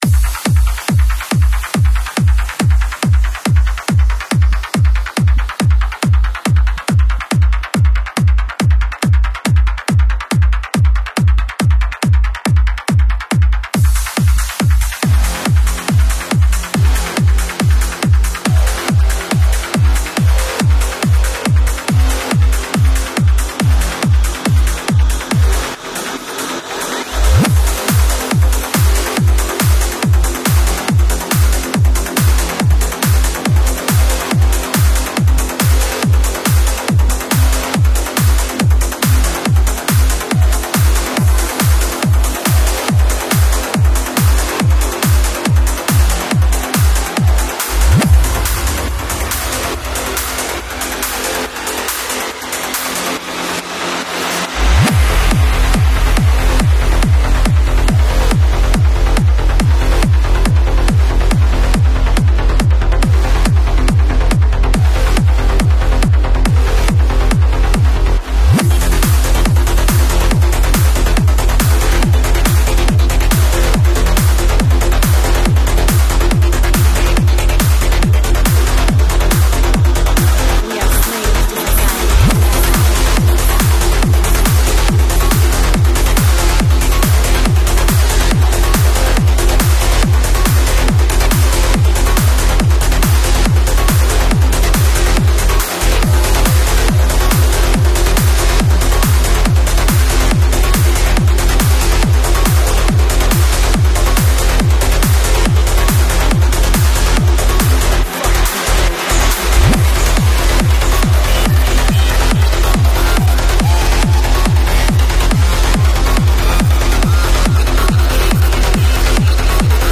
Стиль: Trance / Tech Trance